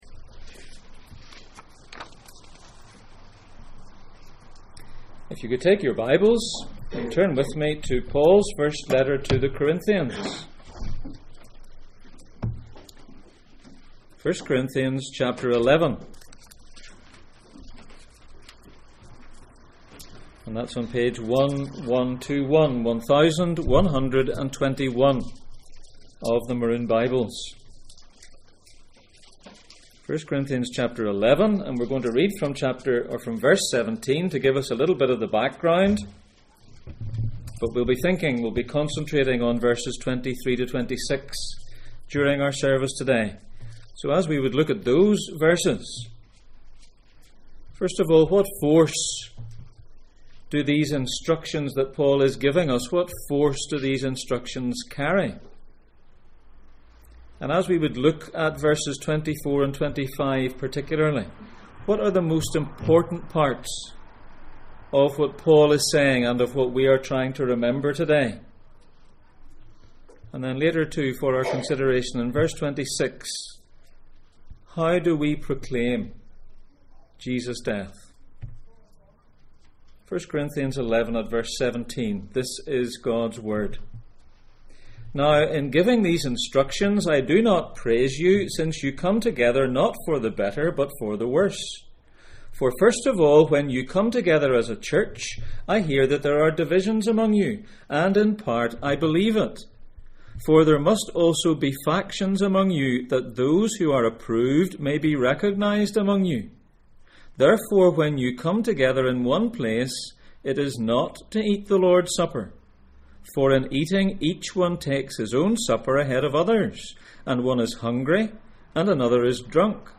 Series: The Lord's Table Passage: 1 Corinthians 11:17-29 Service Type: Sunday Morning